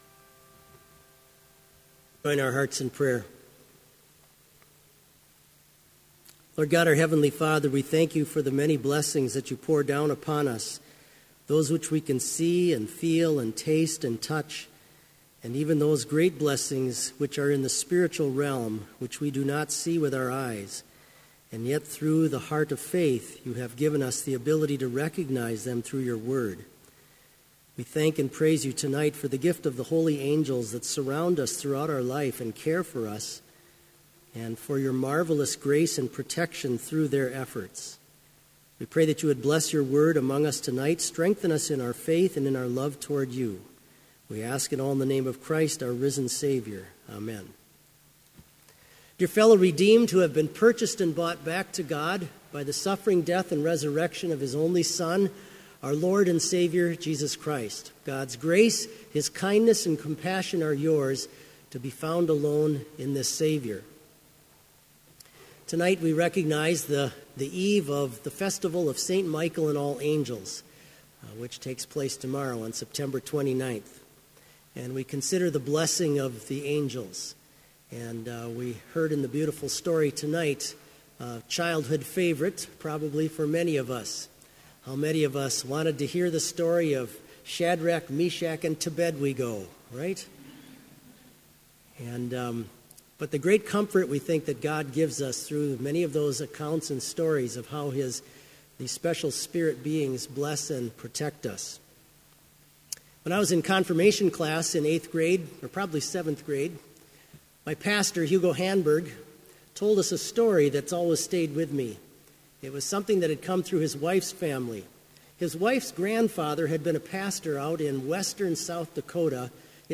Complete service audio for Evening Vespers - September 28, 2016